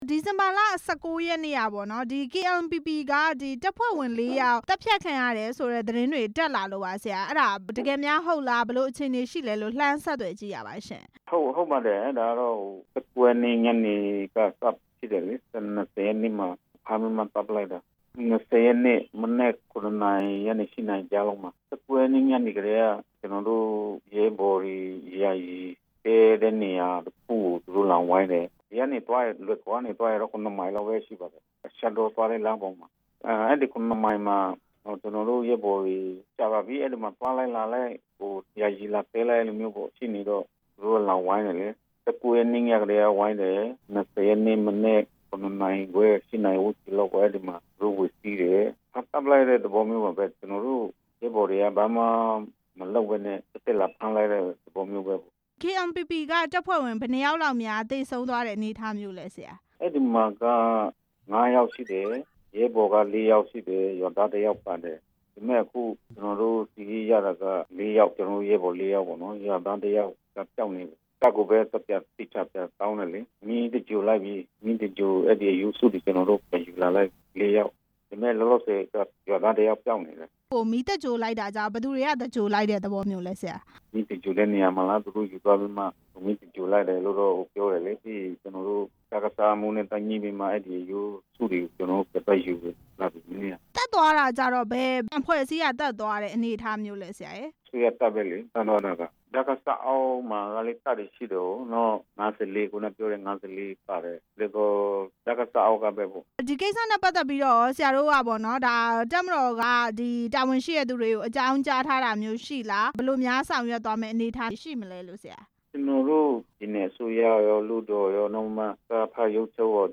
KNPP တပ်ဖွဲ့ဝင်တွေ အသတ်ခံရတဲ့အကြောင်း ဆက်သွယ်မေးမြန်းချက်